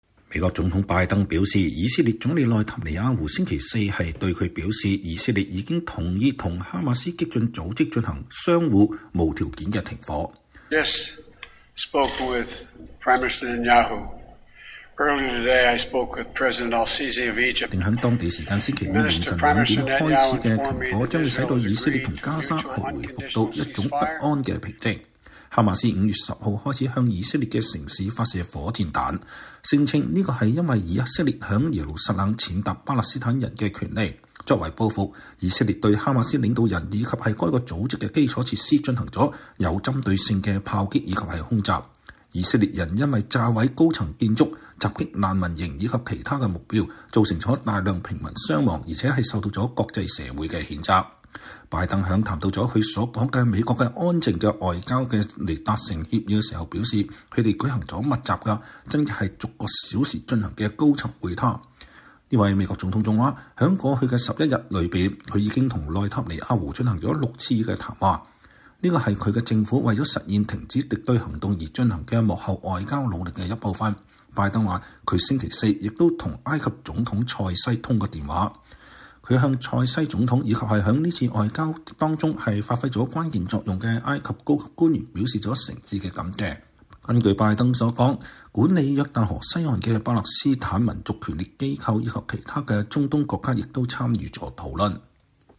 拜登總統在白宮十字廳談以色列與哈馬斯停火。(2021年5月20日)